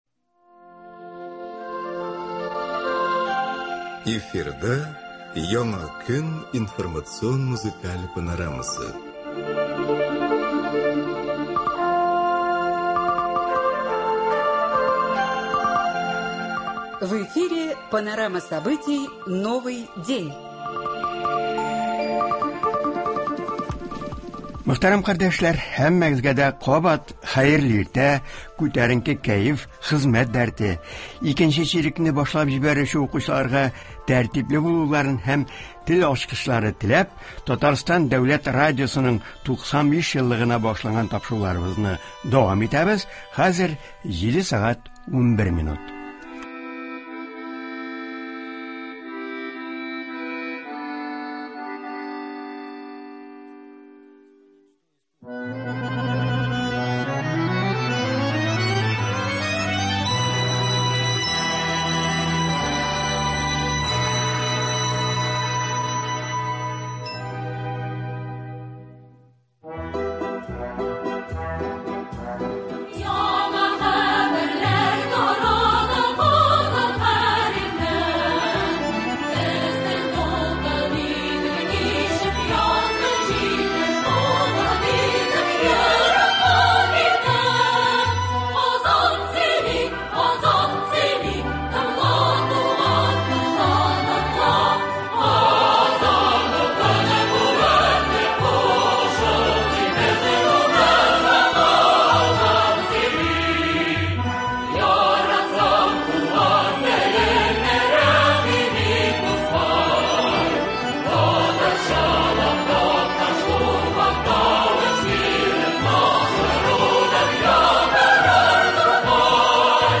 7 ноябрь — Татарстан дәүләт радиосының туган көне, беренче тапшыру эфирда яңгыраганга 95 ел. Тапшыру тулысы белән шушы датага багышлана: республикабыз җитәкчелеге, җәмәгатьчелек вәкилләре радио хезмәткәрләрен юбилей белән тәбрикли, тапшыруда Татарстан Дәүләт Советы рәисе урынбасары, Татарстан Президенты каршындагы туган телне һәм Татарстан республикасында яшәүче халыклар вәкилләренең телләрен саклау һәм үстерү мәсьәләләре буенча комиссия рәисе Марат Әхмәтов һәм башка сәясәтчеләр катнаша.
“Татмедиа” җитәкчелеге, министрлыклар вәкилләре.